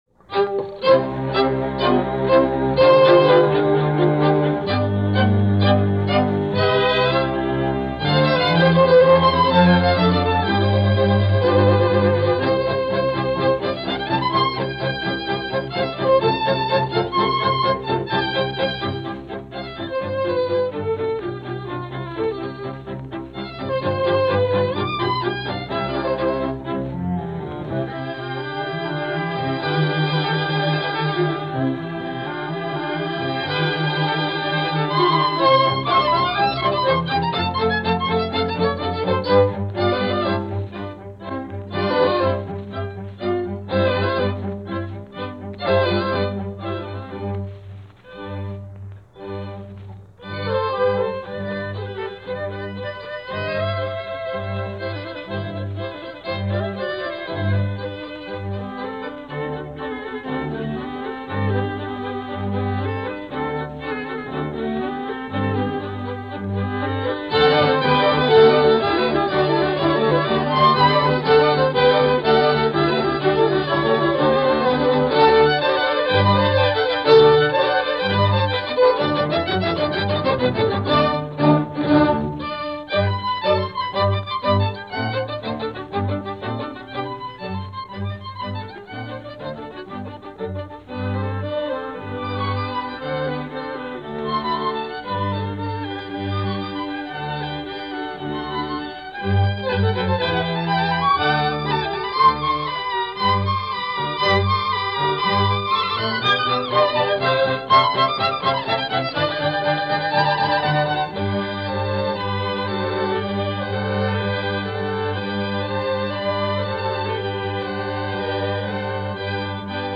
– John Frederick Peter – Quintet For Strings – Eastman Ensemble – Frederick Fennell, cond.
This broadcast, part of the Story Of Music series for NBC Radio was produced by The Eastman School of Music. It features an ensemble of Eastman School musicians, led by the ever-present Frederick Fennell and was recorded on March 20, 1947.
John-Frederick-Peter-Quintet-for-Strings-1947.mp3